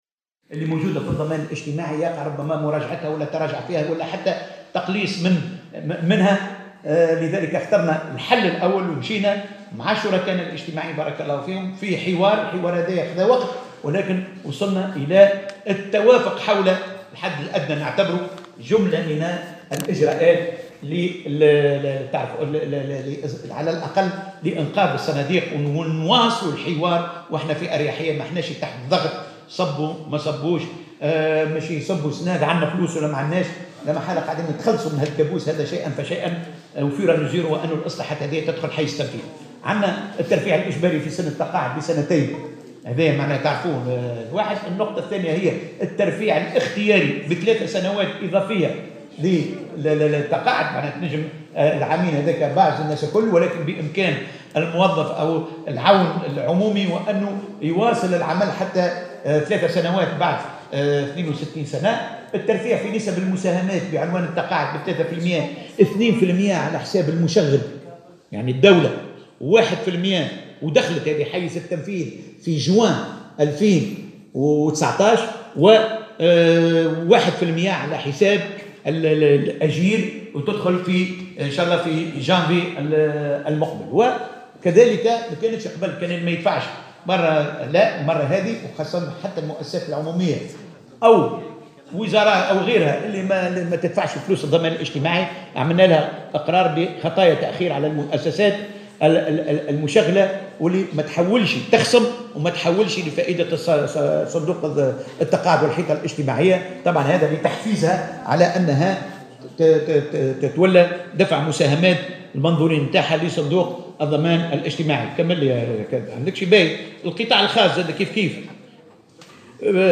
قال وزير الشؤون الإجتماعية محمد الطرابلسي في تصريح لمراسل الجوهرة "اف ام" إن تنويع مصادر المساهمة الإجتماعية لفائدة صناديق الضمان الإجتماعي ستمكن من تعبئة 450 مليون دينار لفائدة هذه الصناديق.